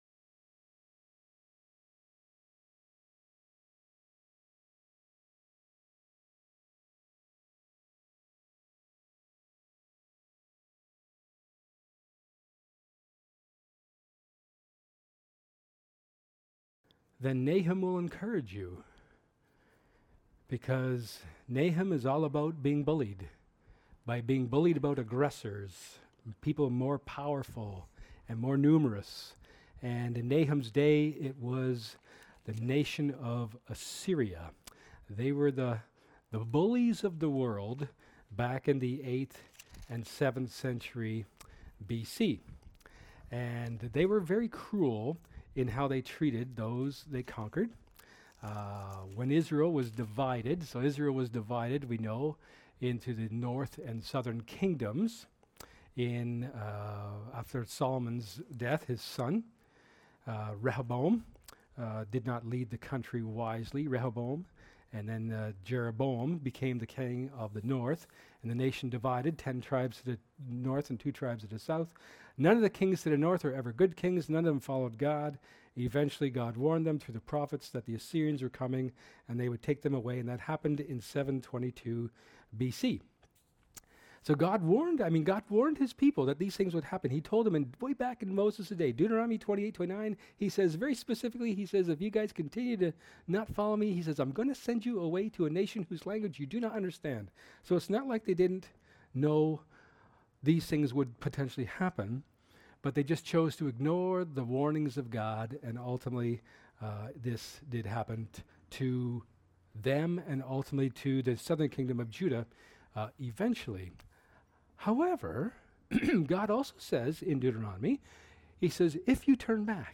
Nahum 2 Service Type: Sermon